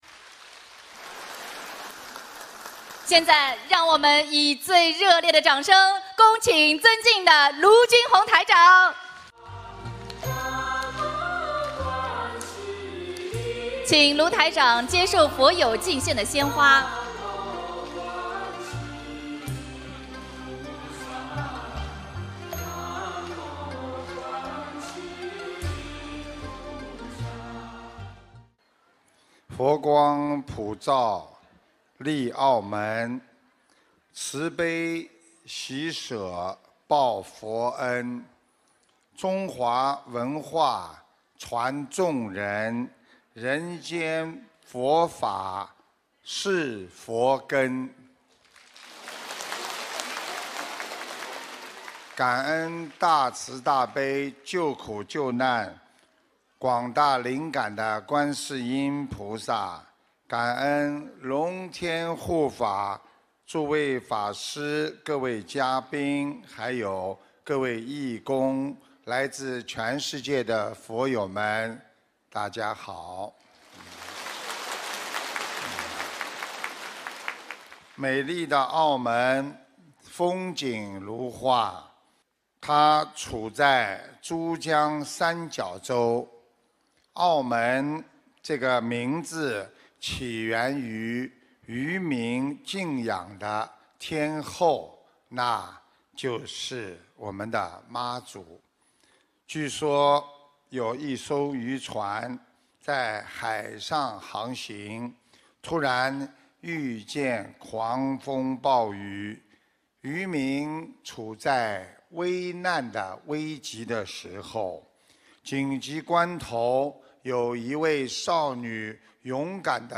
中国澳门